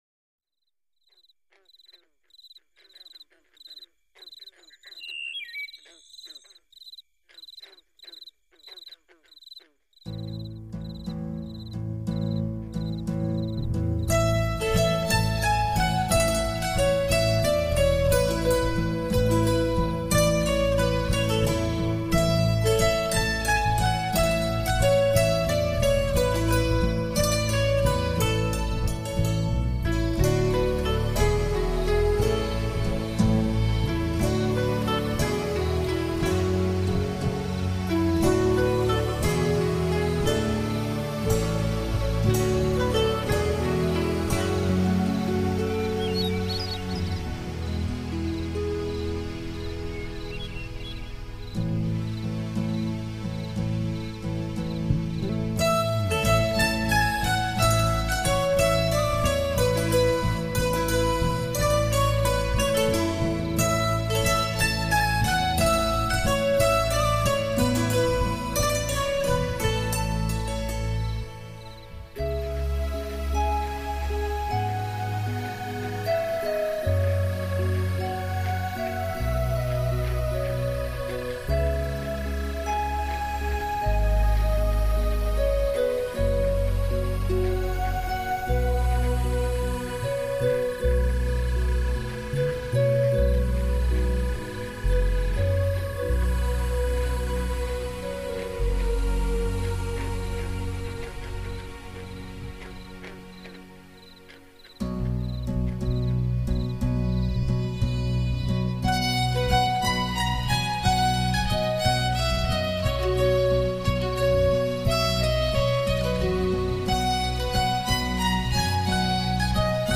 音乐风格： New Age，Celtic，Celtic Fusion
禽鸟和蟋蟀的声音，轰隆隆的雷声在远处，静静的海浪爱抚岸边，